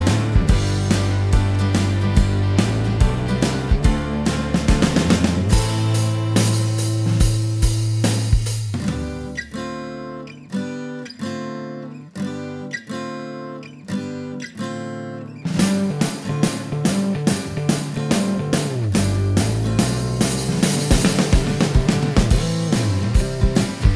Minus Lead Guitar Indie / Alternative 3:05 Buy £1.50